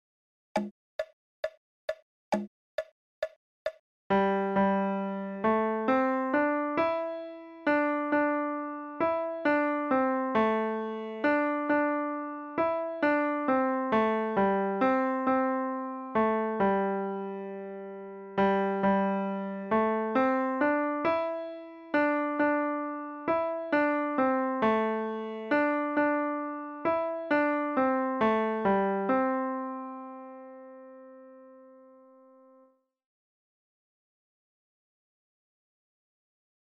Free Piano Music!